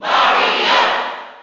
File:Mario Cheer NTSC SSB4.ogg
Mario_Cheer_NTSC_SSB4.ogg.mp3